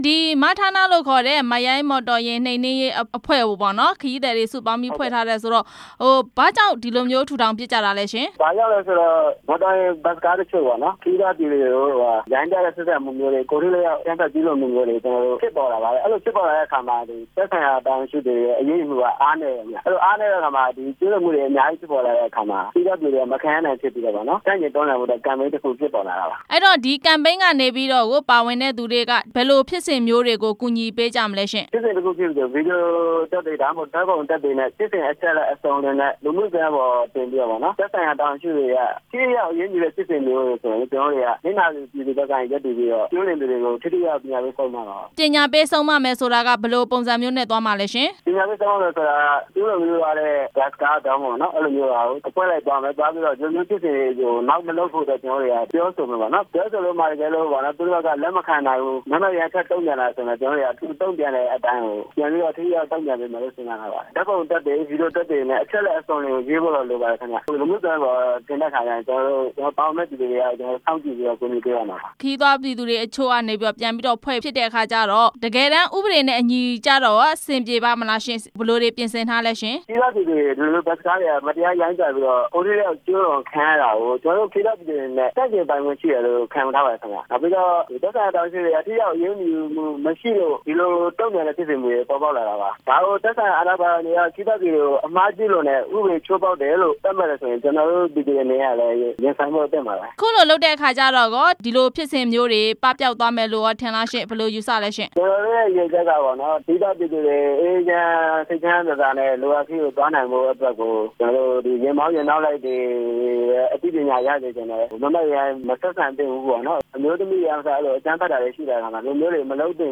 မိုက်ရိုင်း မော်တော်ယာဉ်နှိမ်နှင်းရေး အဖွဲ့ဝင်နဲ့ မေးမြန်းချက်